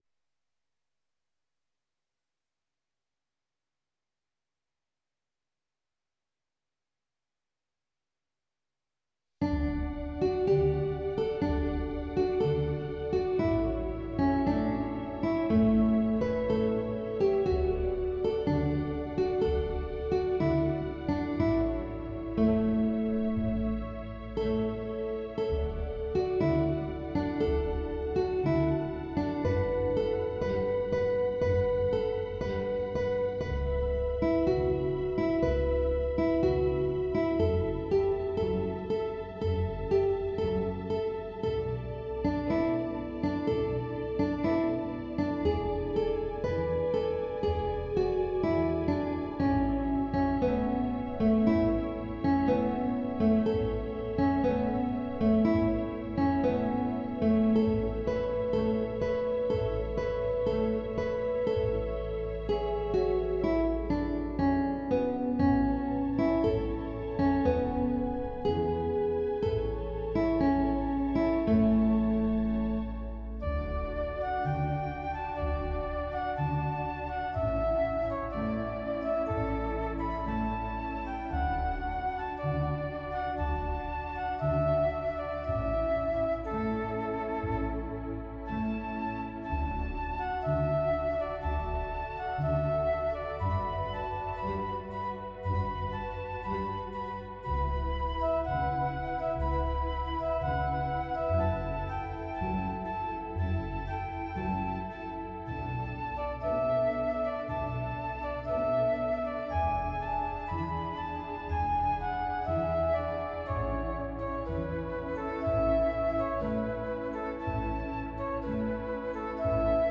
This organ arrangement features the amazing Guitar and String samples that are in the Rodgers Orchestral Library, a standard feature on most Rodgers organs.
Vivaldi_Conc_Lute_Orch.mp3